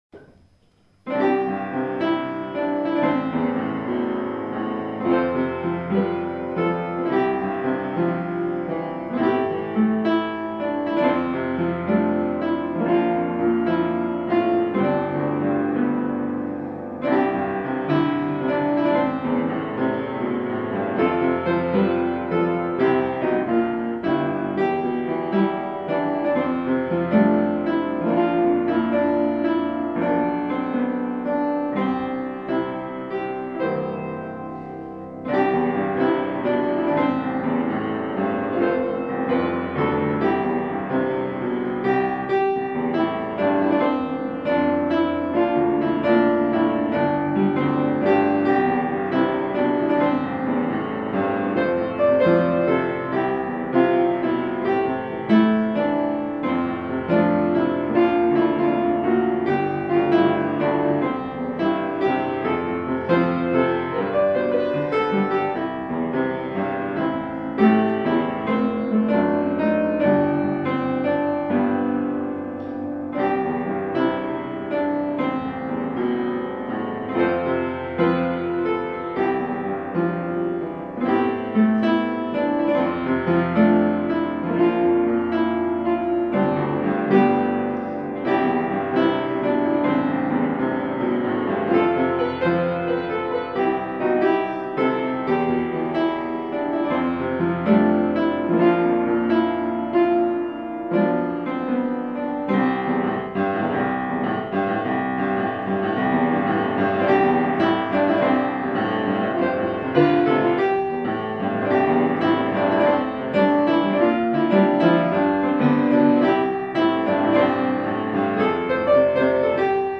ההרמוניה המעניינת מוסיפה.
חלק הרמוני לא מוכר באקורדים.